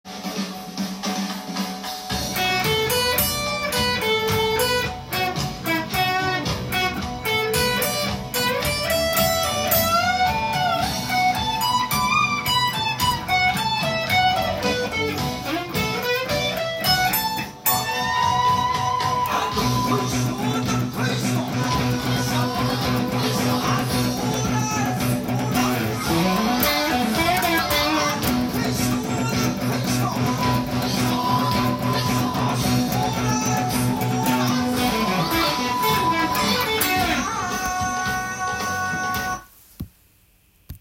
ロック風tab譜
音源に合わせて譜面通り弾いてみました
ギターパートは１５秒から始まるドラムに合わせて笛との
keyがBmになるので
スケールがBmペンタトニックスケールになります。
歌が始まるとロックの定番のブリッジミュートで